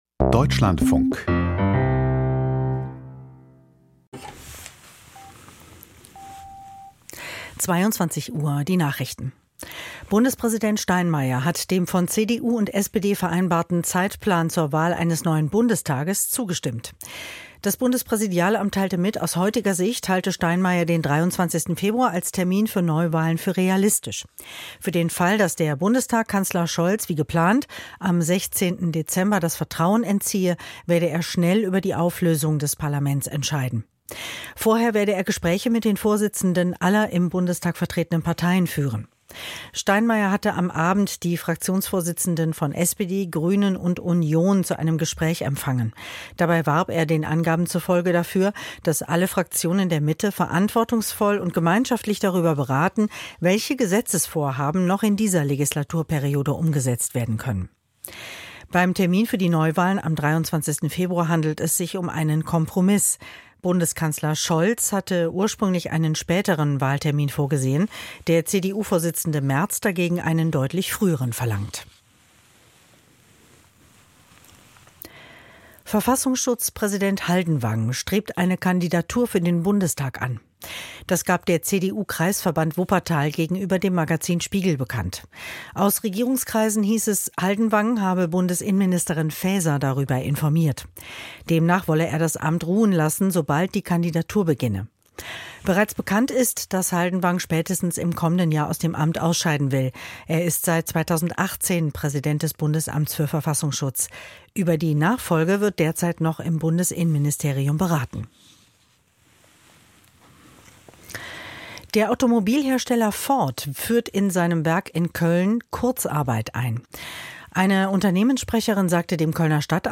Die Deutschlandfunk-Nachrichten vom 12.11.2024, 21:59 Uhr